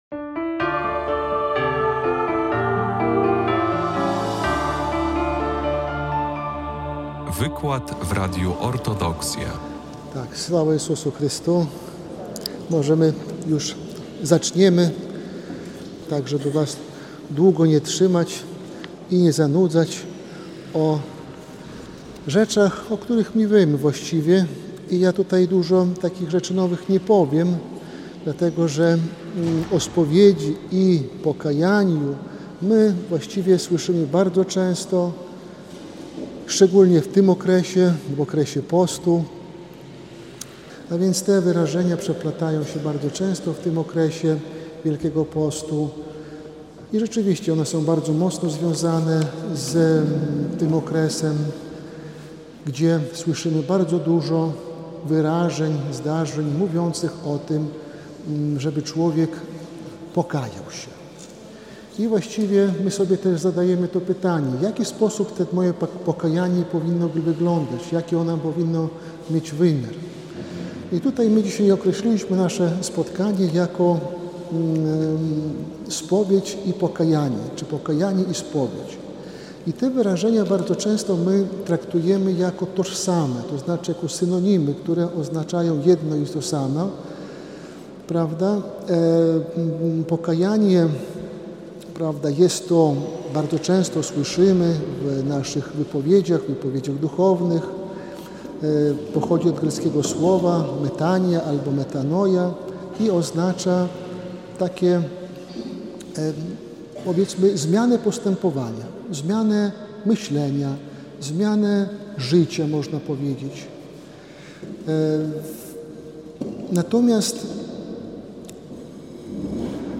w parafii św. Jerzego Zwycięzcy w Białymstoku
wykład